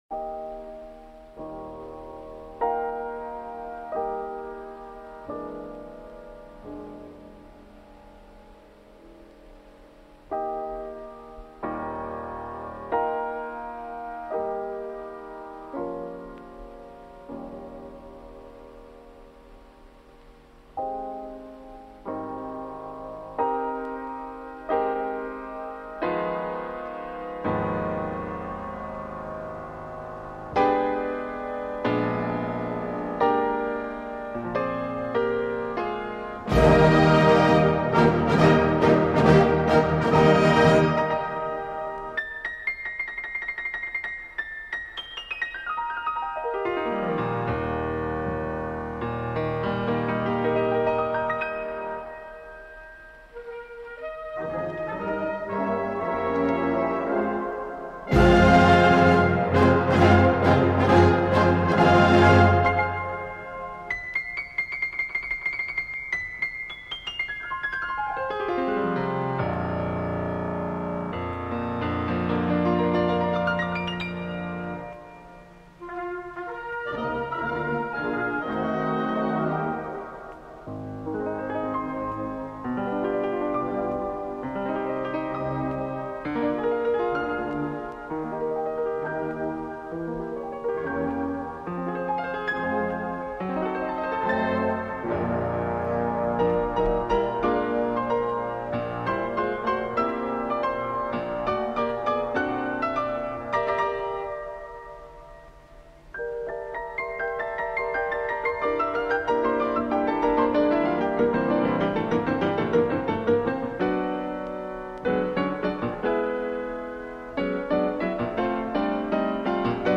Solo für Klavier und Blasorchester Format